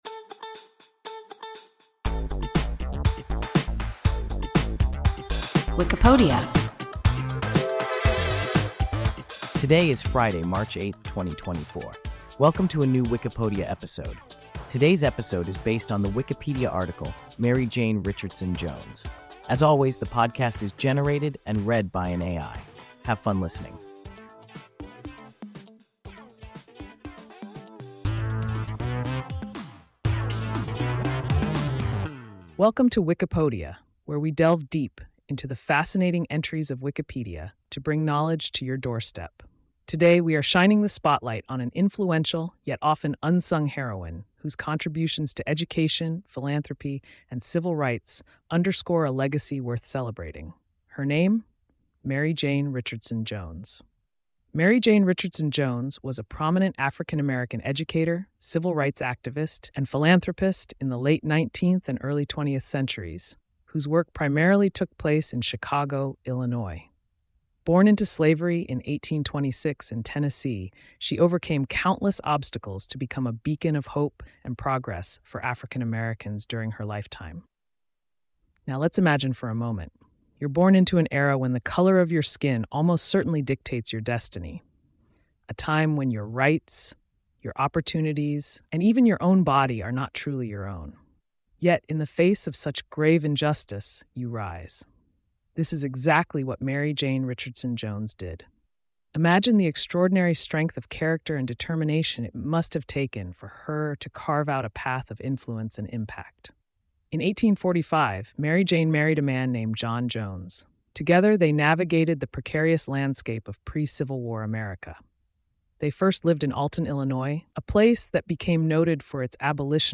Mary Jane Richardson Jones – WIKIPODIA – ein KI Podcast